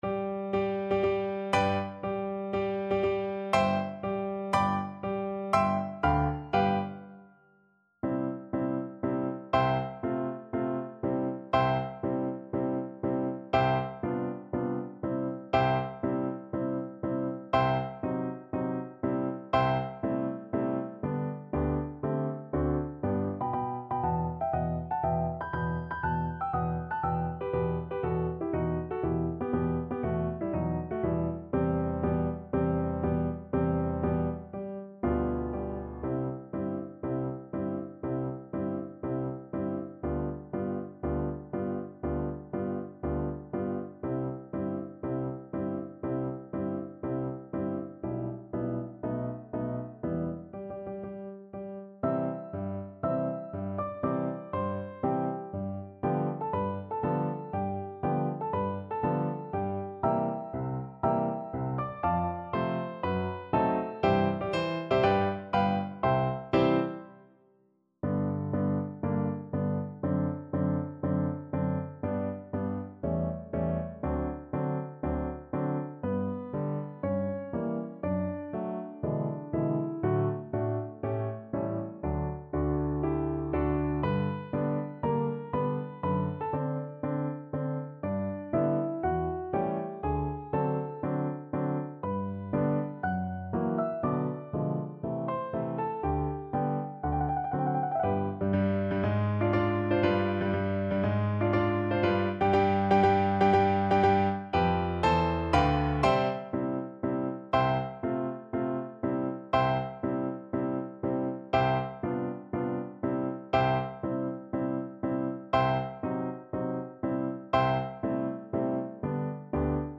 Allegro marziale (View more music marked Allegro)